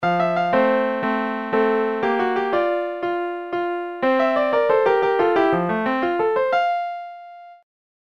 Rolandの MIDIキーボード(76鍵)
※わざとゲームぽく弾いています
[るんるん]ピアノのおけいこ6 （スティーブン・コリンズ・フォスターの「夢路より」から。120bpm）